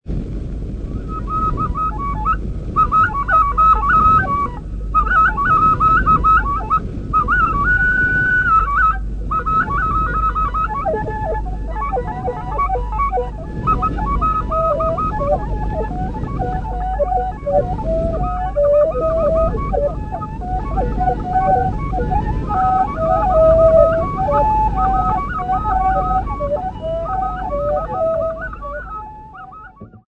Abalere ba Kabaka
Folk music--Africa
Field recordings
Africa Uganda Kampala f-ug
sound recording-musical
Topical song accompanied by 3 Ndere flutes, end blown, notched, open, 1 cylindrical drum, pinned, footed, 3 conical drums, laced 2 played with hands and 1 with sticks.